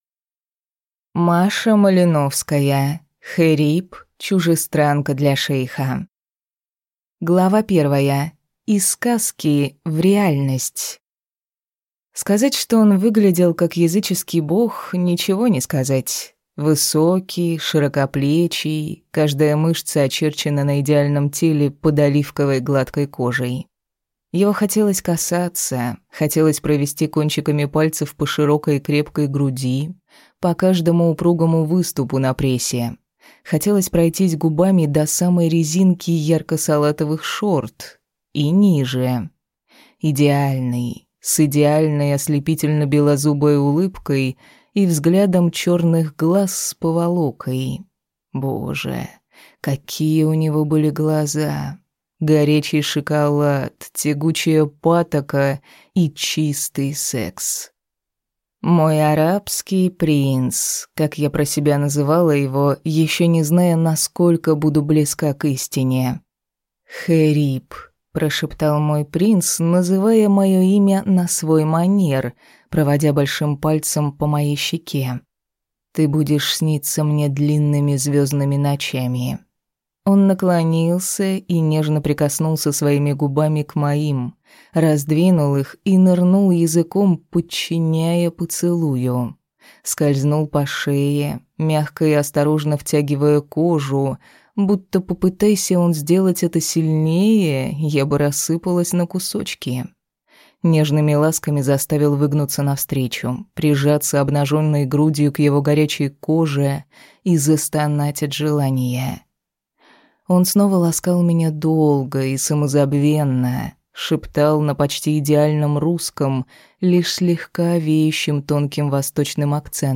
Аудиокнига Хэриб. Чужестранка для шейха | Библиотека аудиокниг